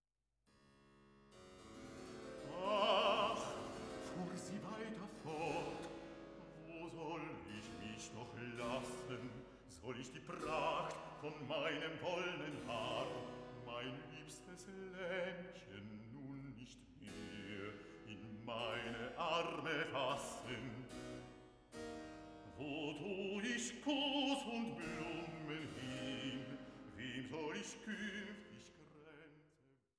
Arie für Sopran, Streicher und Basso continuo